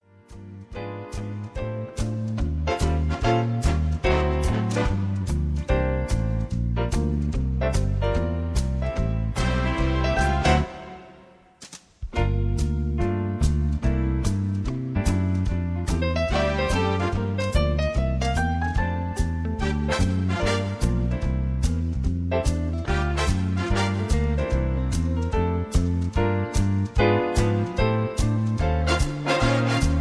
karaoke mp3 tracks